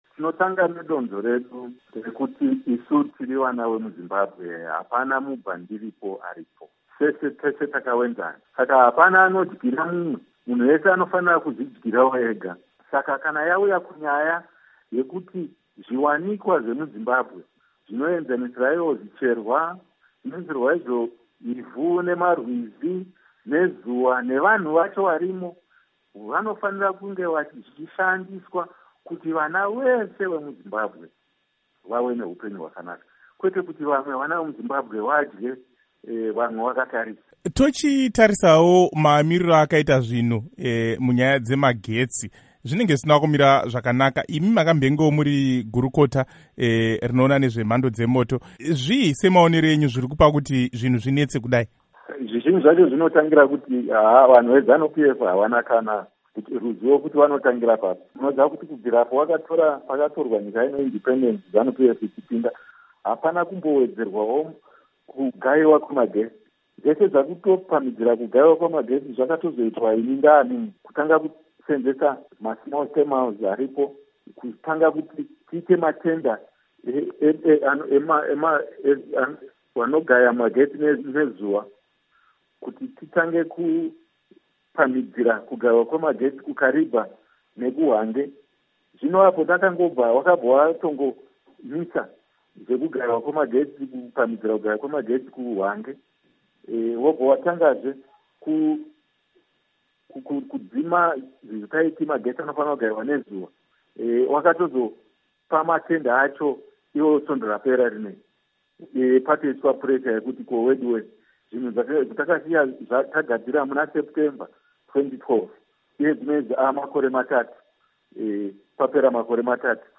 Hurukuro naVaElton Mangoma